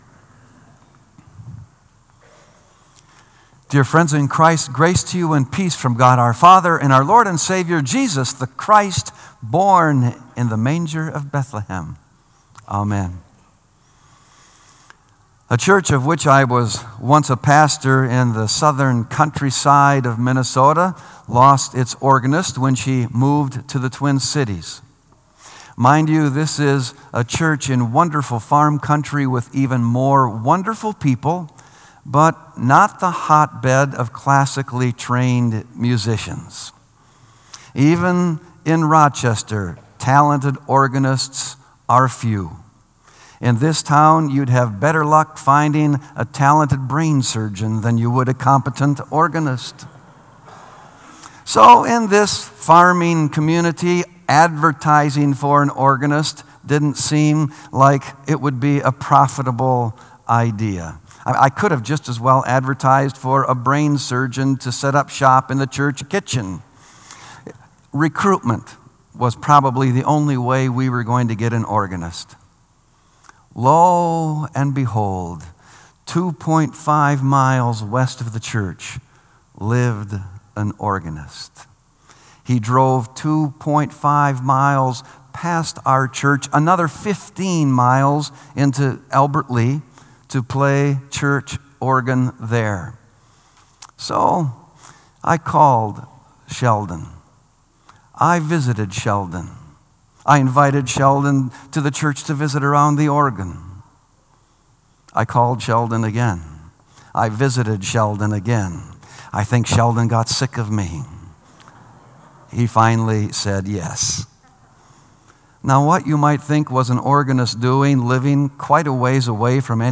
Sermon “Back To The Shepherds”